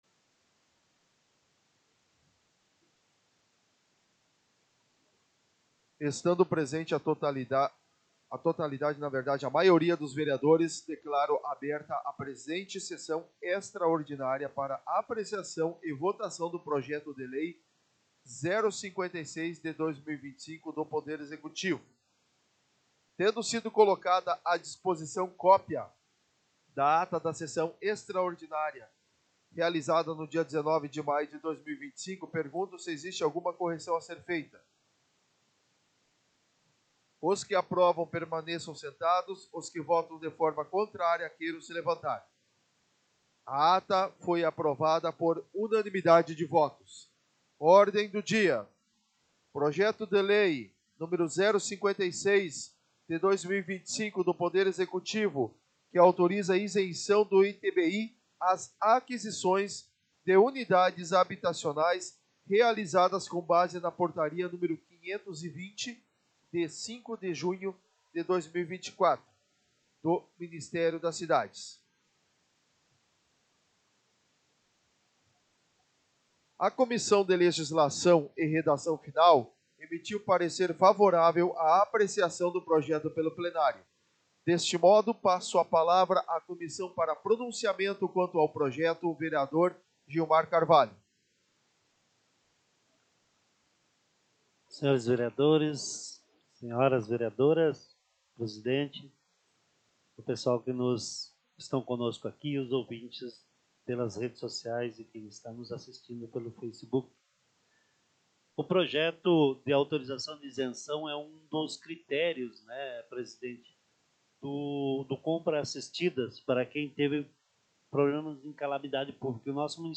Áudio Sessão Extraordinária 26.05.2025